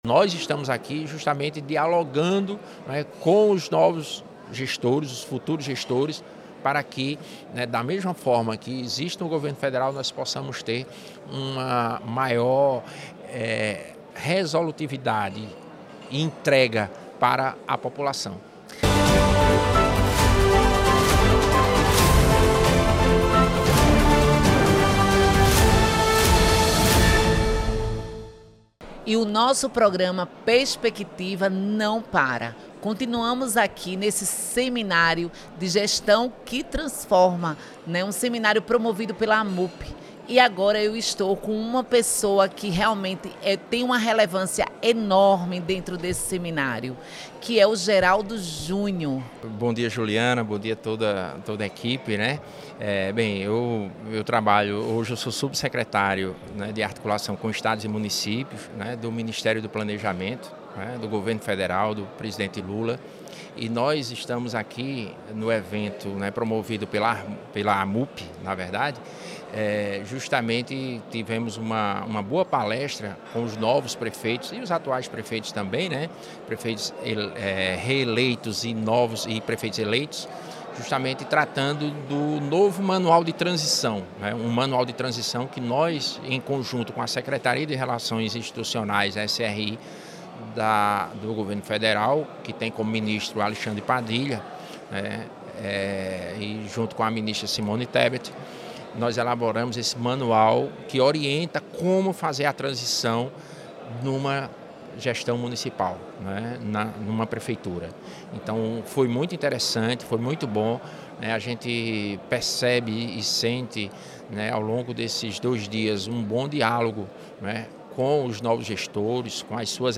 Em entrevista ao programa Perspectiva da Rede Você